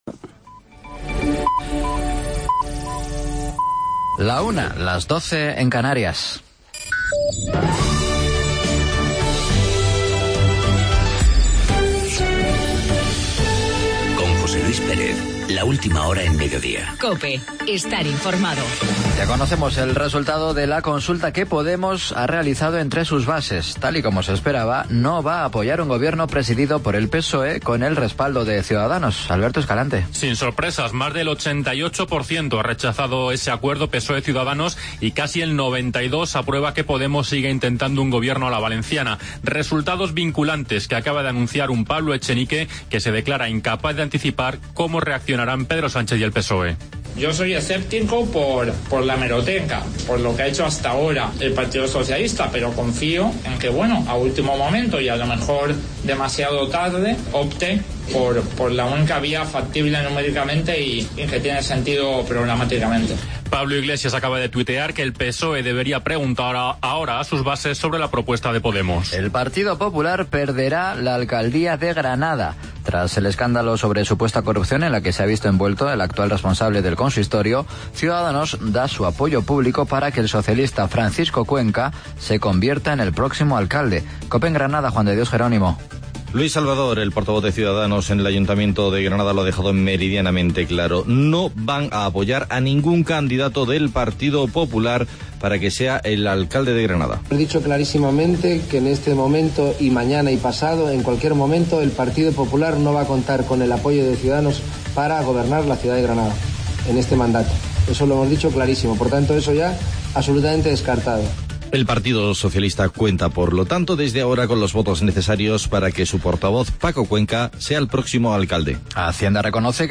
AUDIO: Boletín, avance informativo